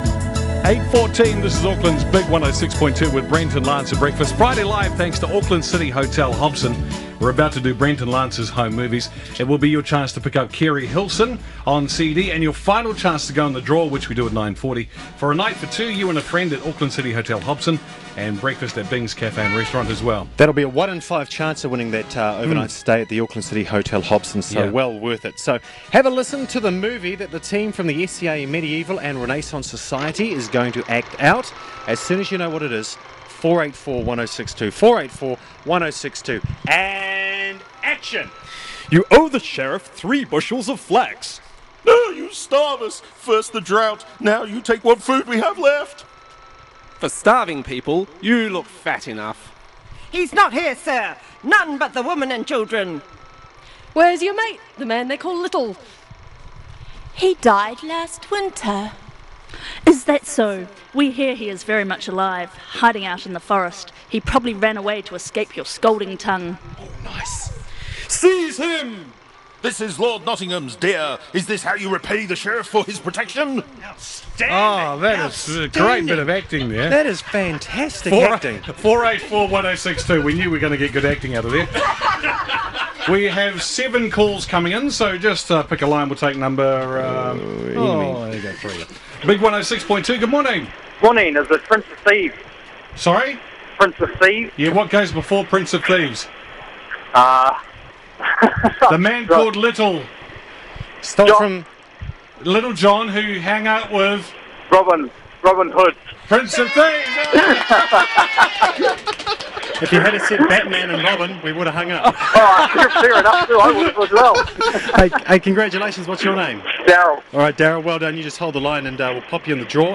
More correctly, the hosts of BigFM invited us to come along and be part of their Friday breakfast show and to talk about who we are and what we do.